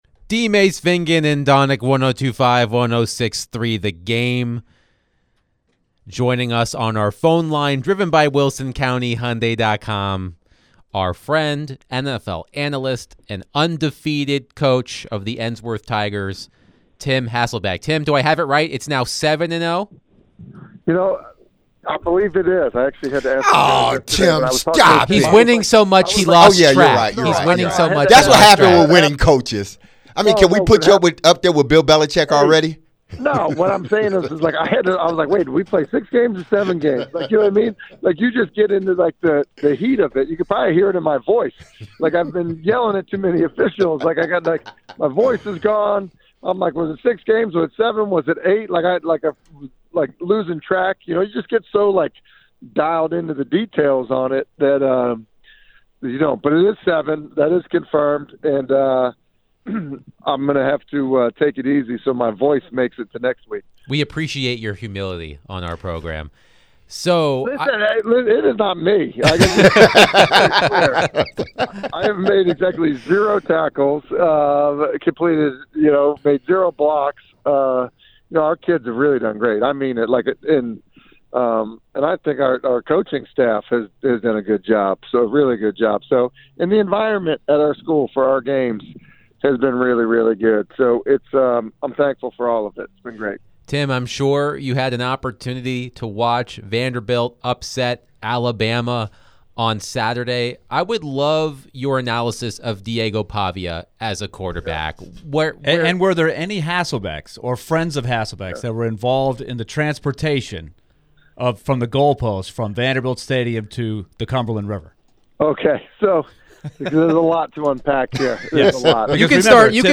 ESPN NFL Analyst Tim Hasselbeck joined the show to talk about the Titans' matchup with the Colts and the Jets' firing of Robert Saleh.